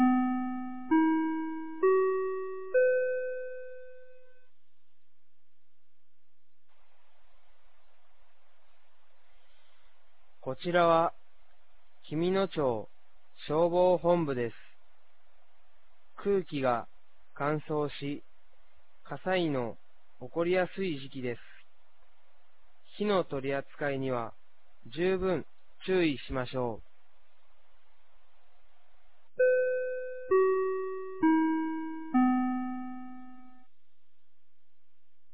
2025年11月22日 16時00分に、紀美野町より全地区へ放送がありました。